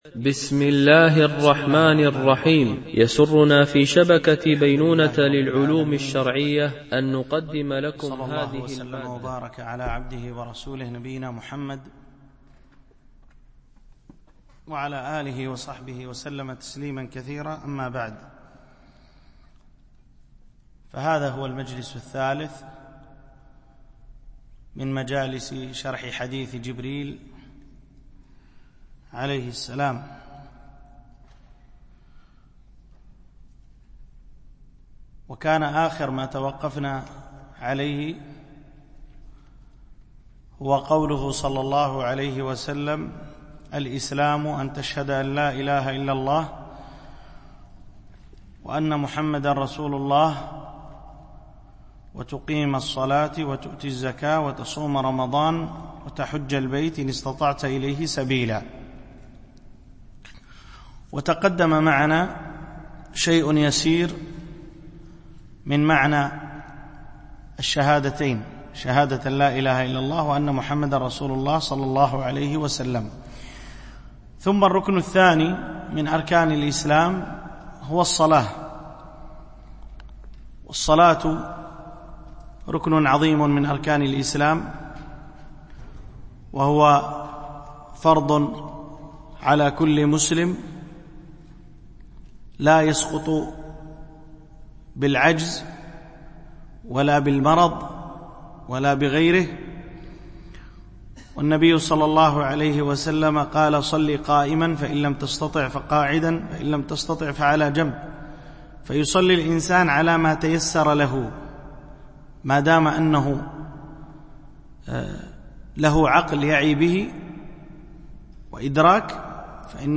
شرح حديث جبريل في بيان مراتب الدين - الدرس 3